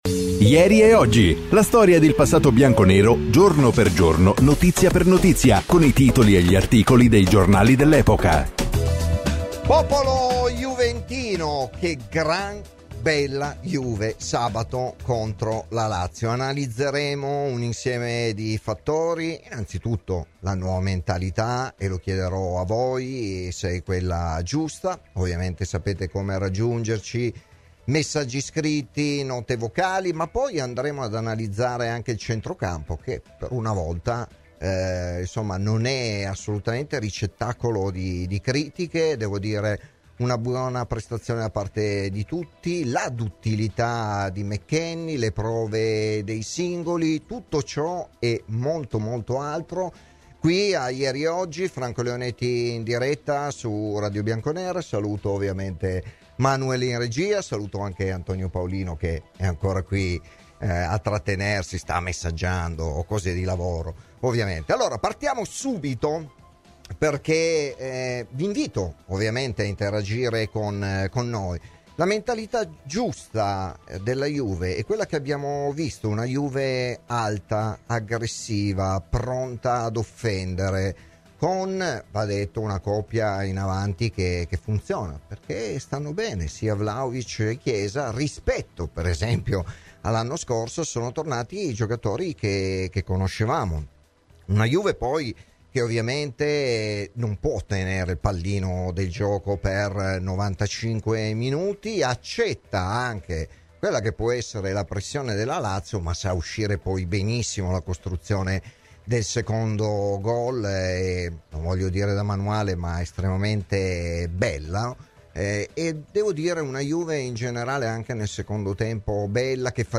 Intervistato da Radio Bianconera nel corso di 'Ieri e Oggi'